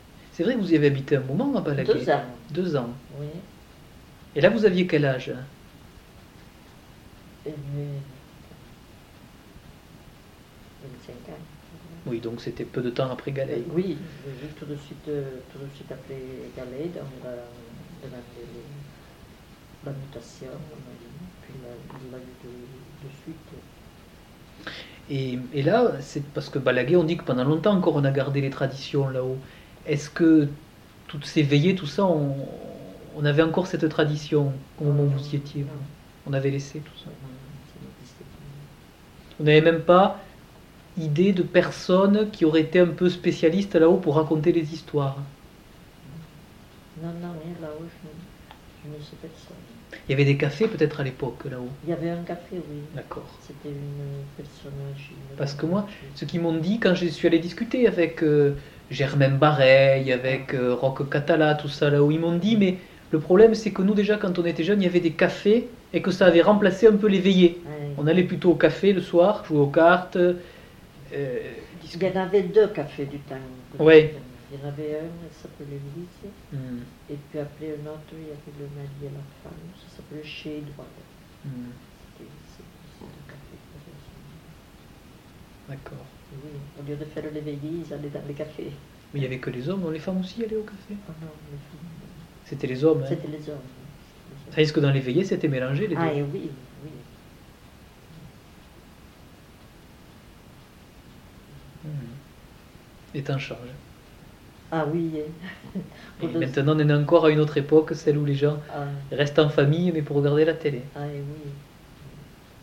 Lieu : Alas (lieu-dit)
Genre : témoignage thématique